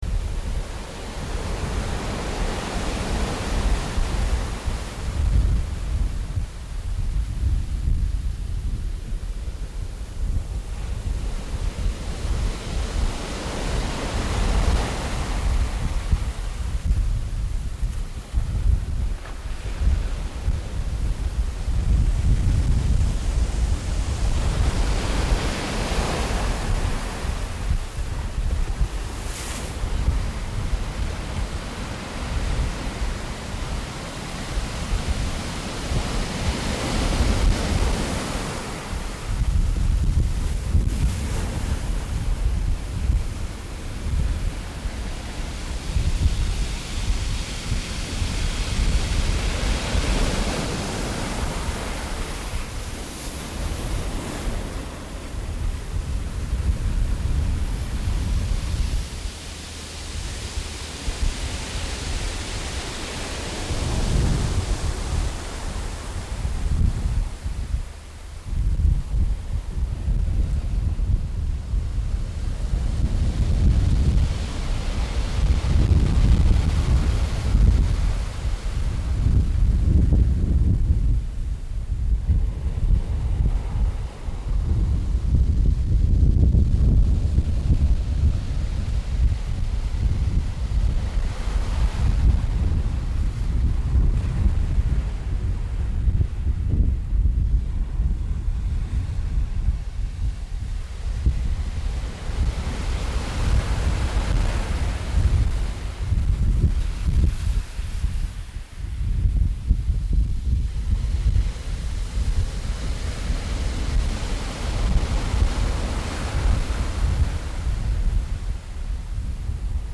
nami.mp3